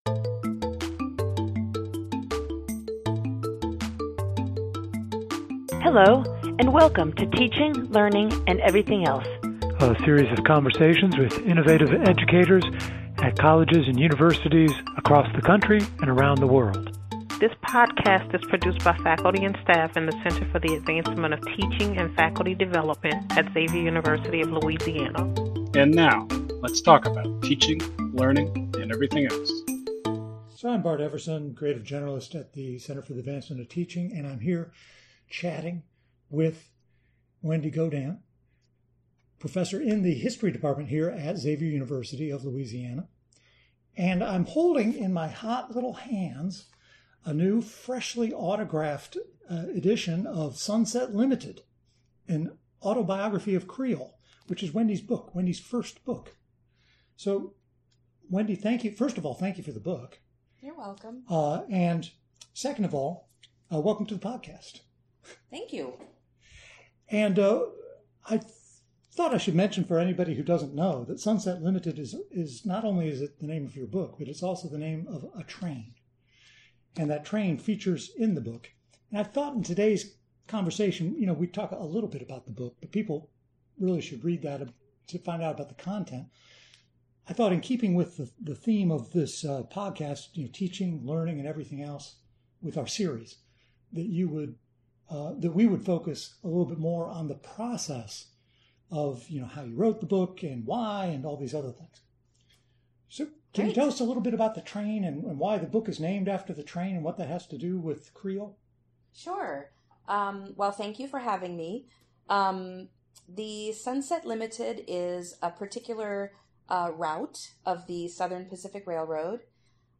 Each episode consists of a conversation with a teacher in higher education.